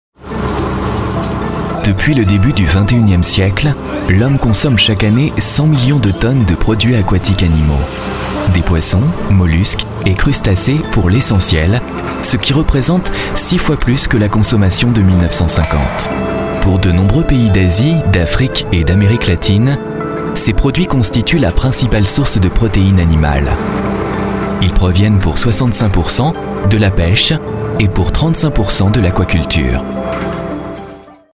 Pêche - Comédien voix off
Genre : voix off.